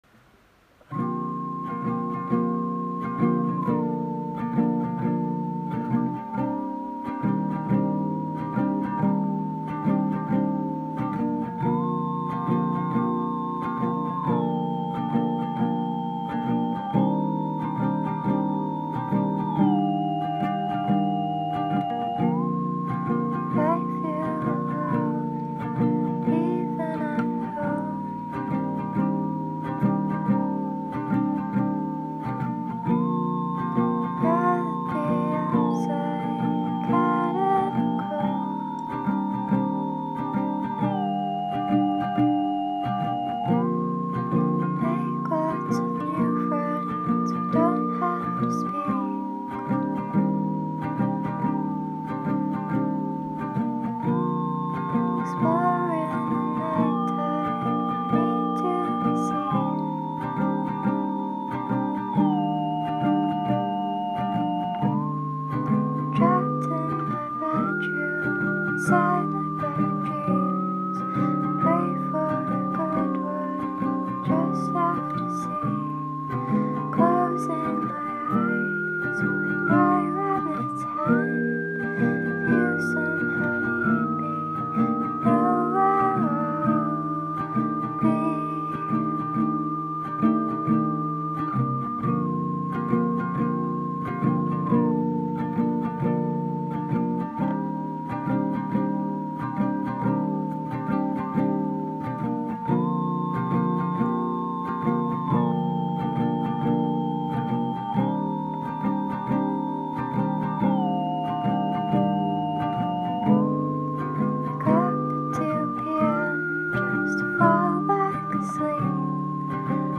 погружает слушателя в атмосферу меланхолии и одиночества